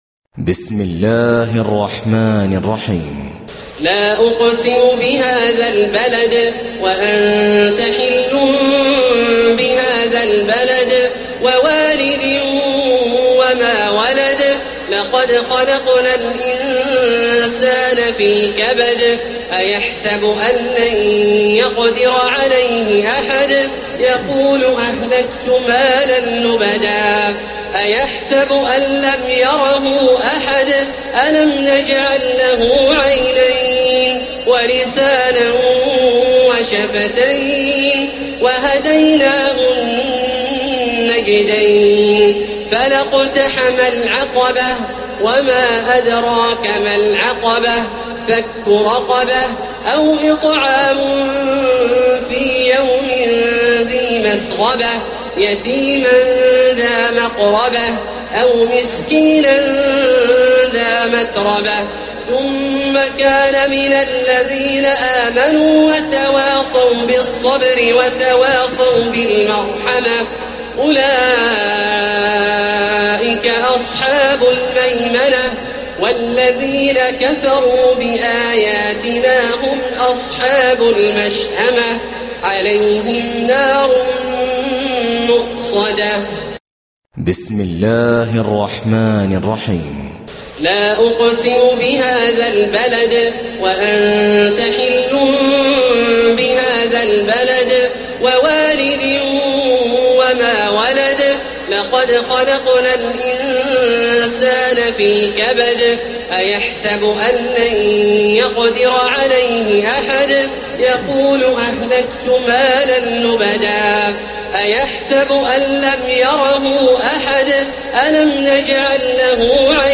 تلاوات
البلد القارئ: فضيلة الشيخ مجموعة مشائخ وعلماء الصنف: تلاوات تاريخ: السبت 24 رمضان 1436 هـ الموافق لـ : 11 جويلية 2015 م رواية : حفص عن عاصم الحجم:340.5K المدة :00:01:27 حمله :106 سمعه :234 سماع التلاوة تحميل التلاوة